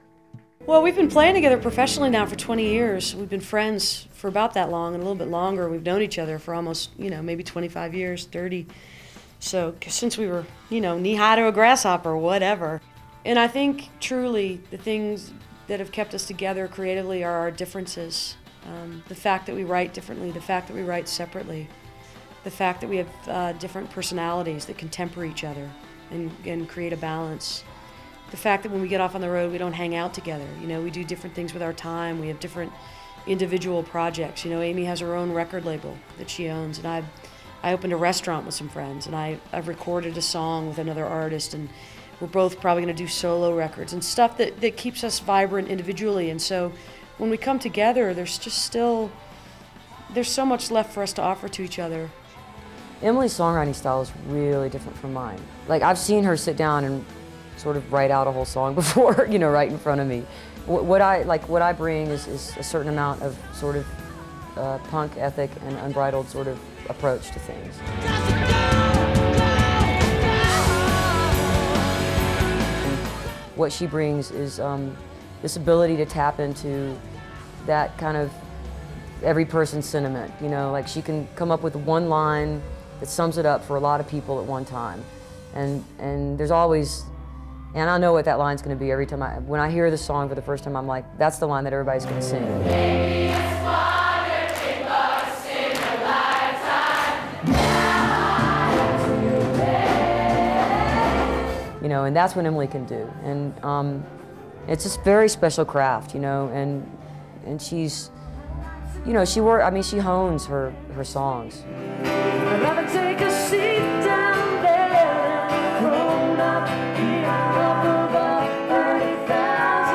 lifeblood: bootlegs: 1999-11-20: fillmore auditorium - denver, colorado (alternate recording 2)
14. interview (3:19)